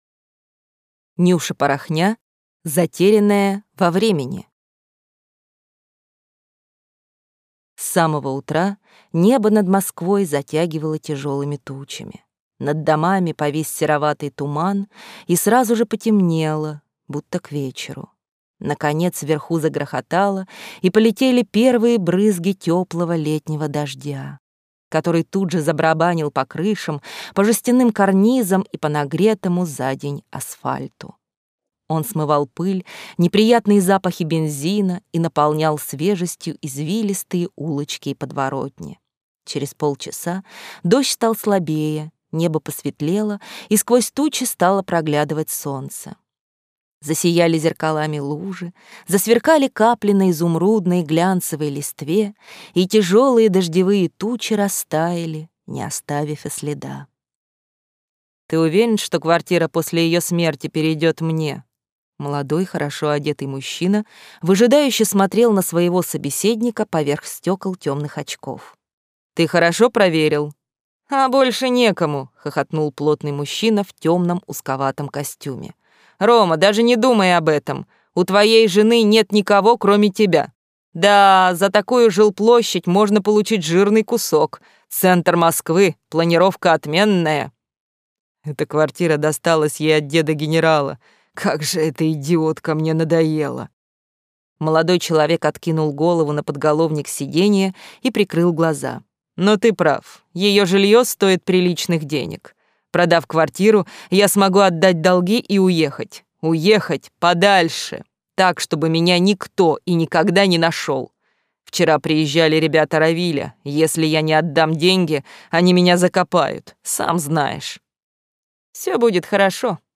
Аудиокнига Затерянная во времени | Библиотека аудиокниг